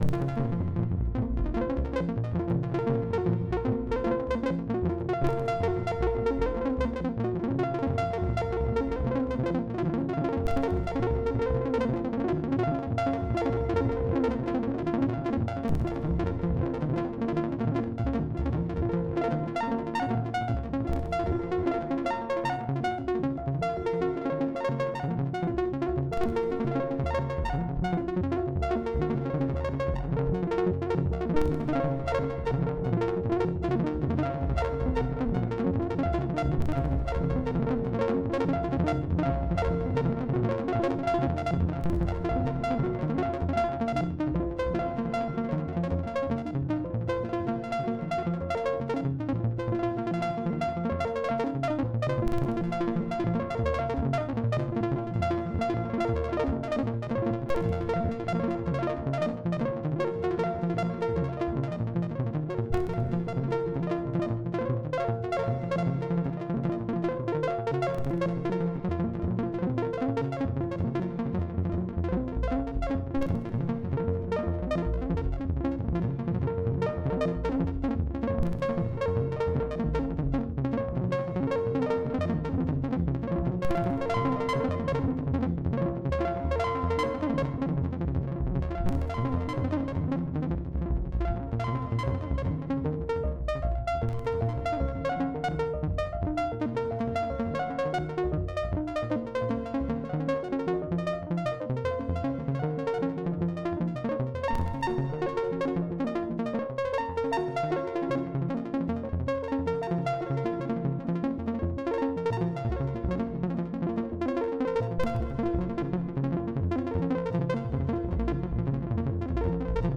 Subject description: Ambiental Melodic Piece   Reply with quote  Mark this post and the followings unread